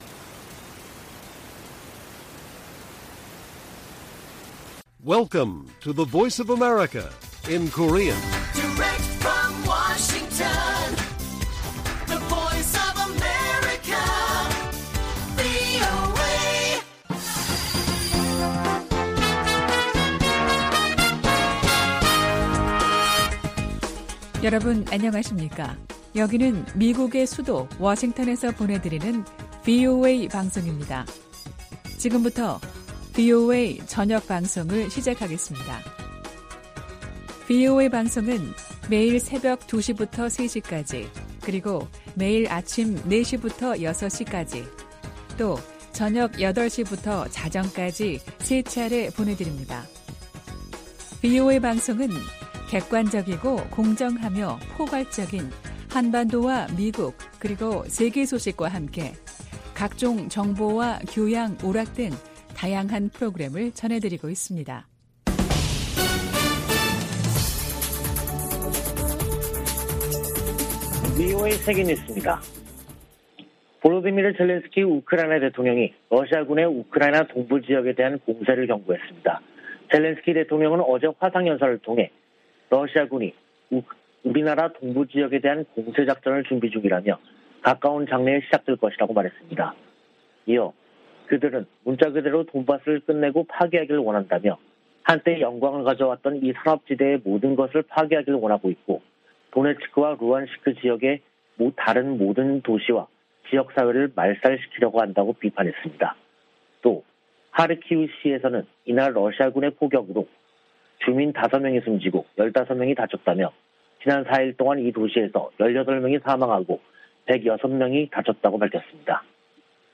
VOA 한국어 간판 뉴스 프로그램 '뉴스 투데이', 2022년 4월 18일 1부 방송입니다. 북한 대외 관영 매체들은 김정은 국무위원장 참관 아래 신형 전술유도무기 시험발사가 성공했다고 보도했습니다. 미국령 괌 당국은 북한이 미상의 발사체를 쏜데 따라 역내 상황을 주시 중이라고 발표했다가 5시간 만에 철회했습니다. 미 국무부는 국제사회가 북한의 제재 회피를 막기위해 광범위한 협력을 하고 있으며 특히 대량살상무기관련 밀수 단속에 집중하고 있다고 밝혔습니다.